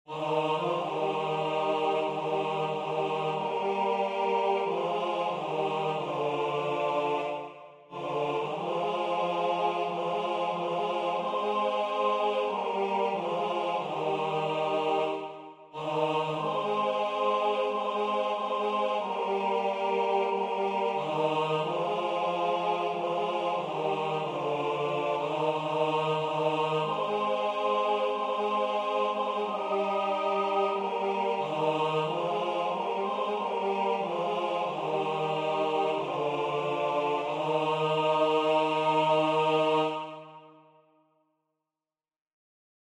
2-stemmig